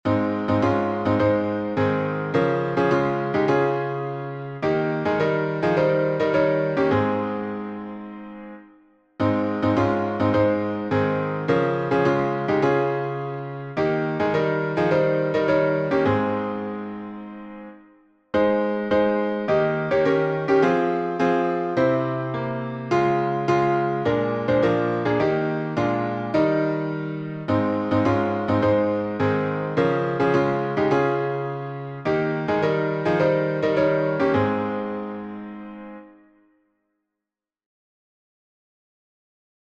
Words by William O. Cushing (1823-1902)George F. Root (1820-1895)Key signature: A flat major (4 flats)Time signature: 4/4Meter: 11.9.11.9. with RefrainPublic Domain1.